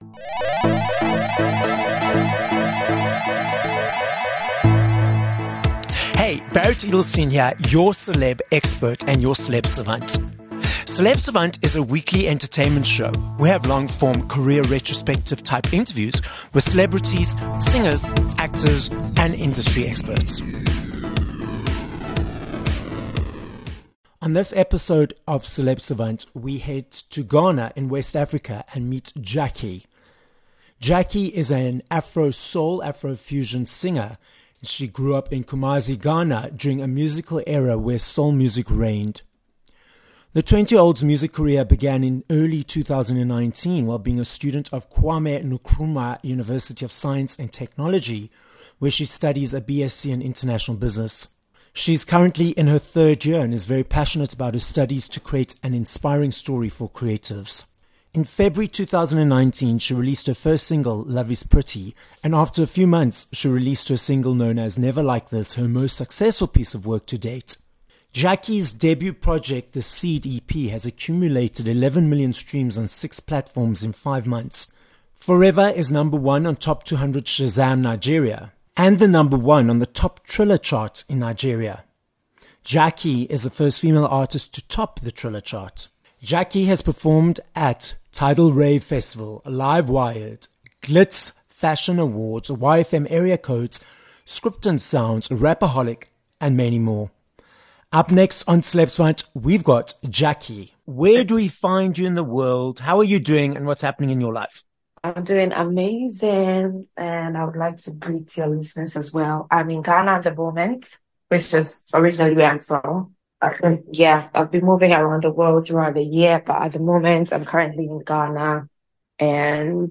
28 Nov Interview with Gyakie